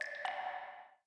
Slide close 2.wav